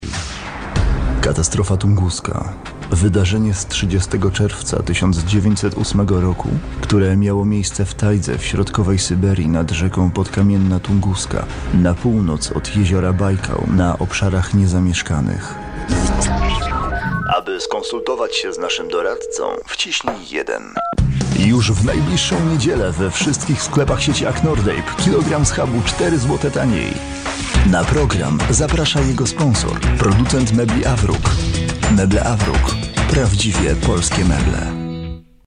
男波07 波兰语男声 宣传片广告 大气浑厚磁性|沉稳|积极向上